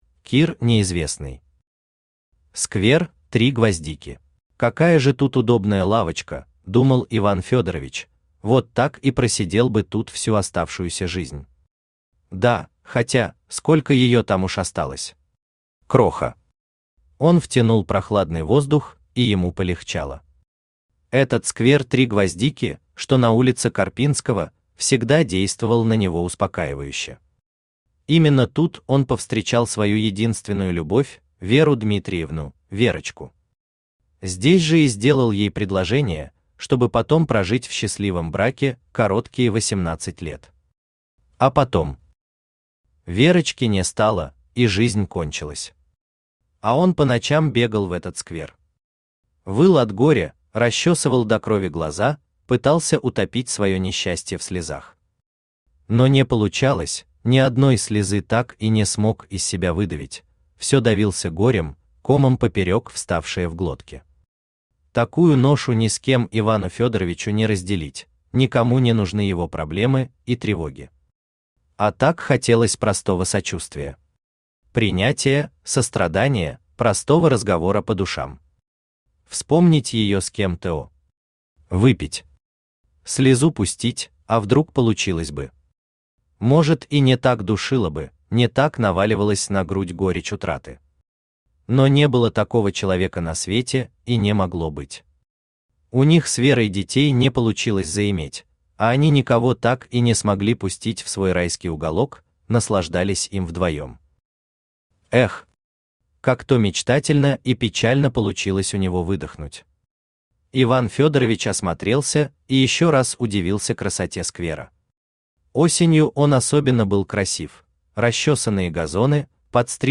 Аудиокнига Сквер «Три гвоздики» | Библиотека аудиокниг
Aудиокнига Сквер «Три гвоздики» Автор Кир Николаевич Неизвестный Читает аудиокнигу Авточтец ЛитРес.